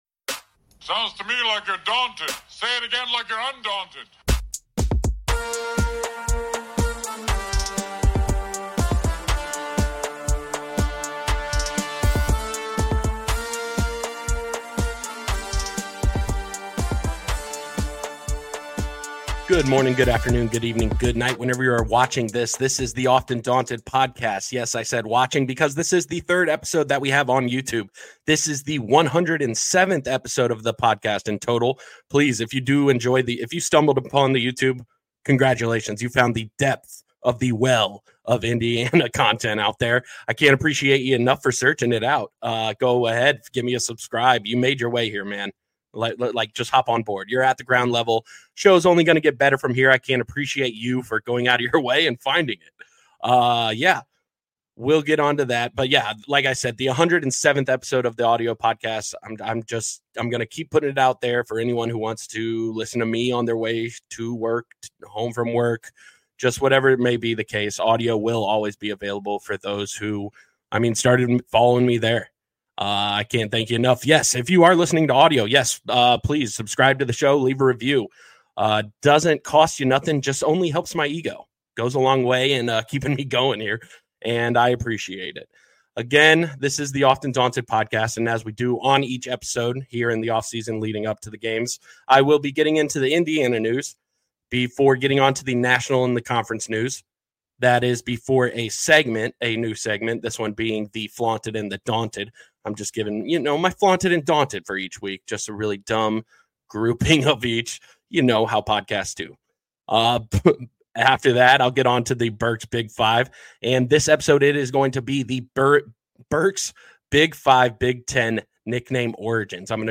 Look for an upgrade in digital microphones on my end.